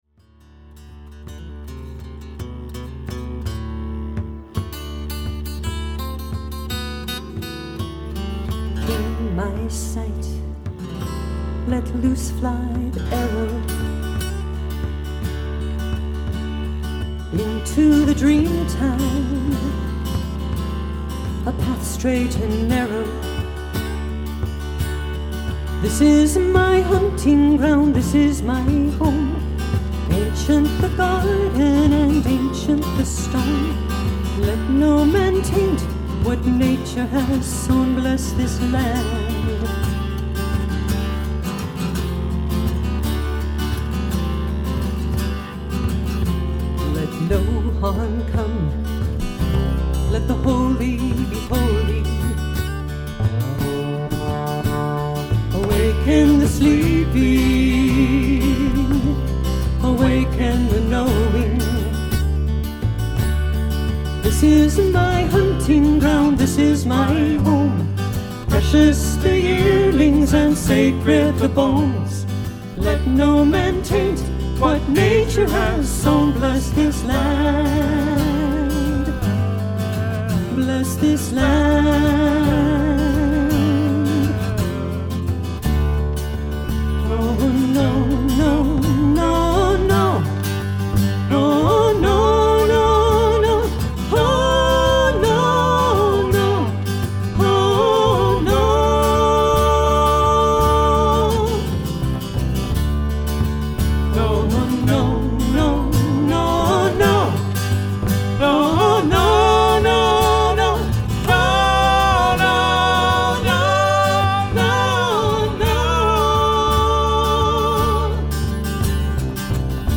lush harmonies, and the pulse of world rhythms.
guitar
bass